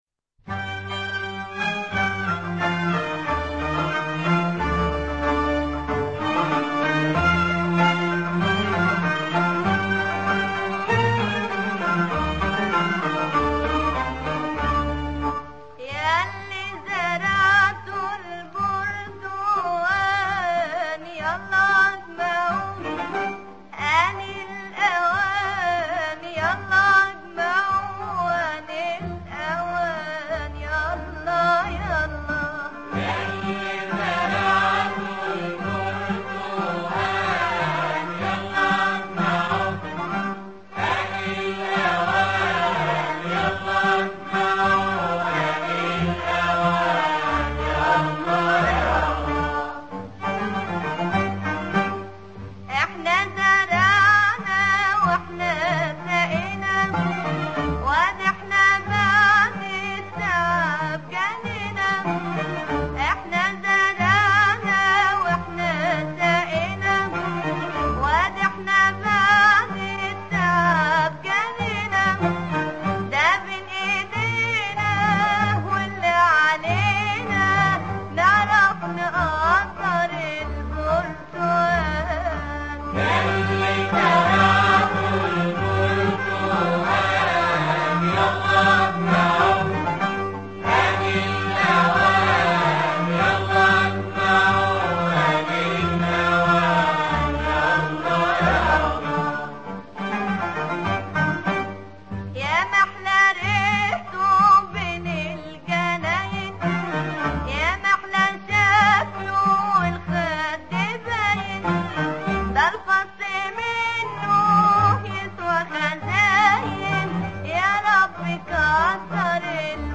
المقام : اللامي
القالب : الطقطوقة